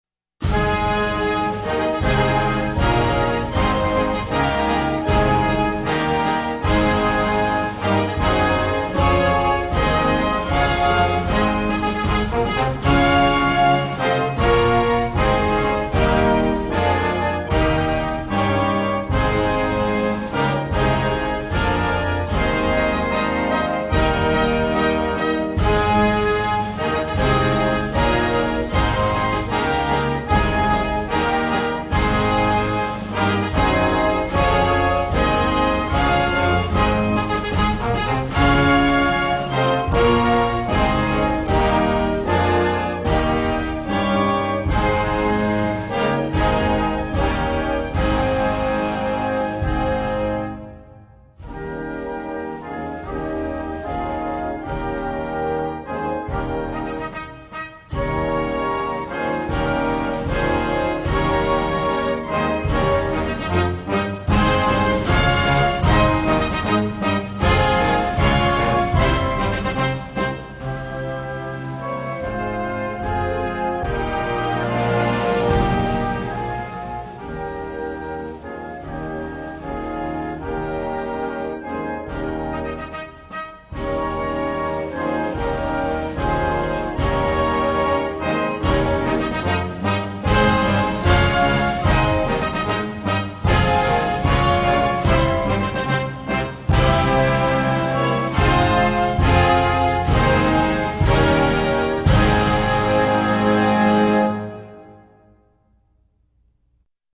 в инструментальном исполнении